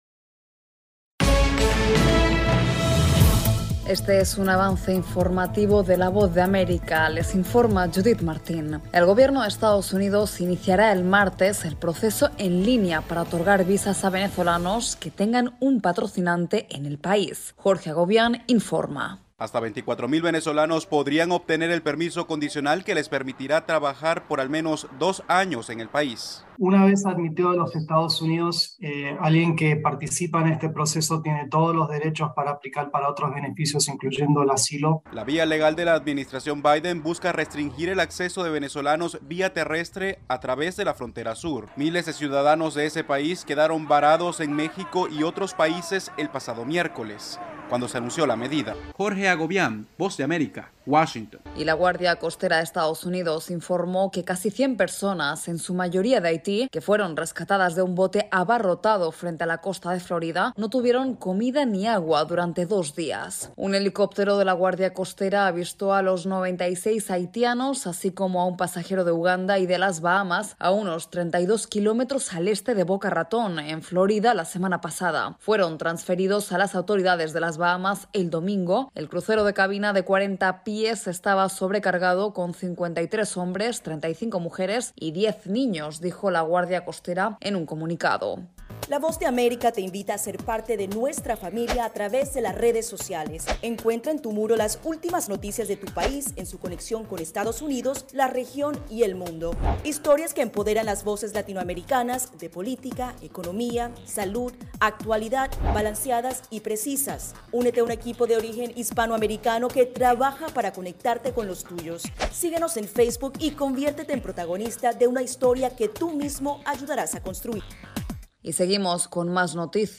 Este es un avance informativo de la Voz de América...